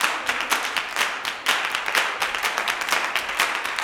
125GCLAPS2-L.wav